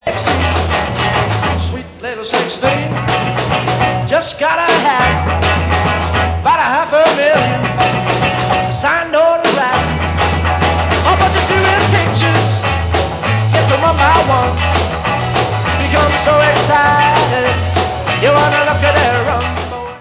sledovat novinky v oddělení Pop/Oldies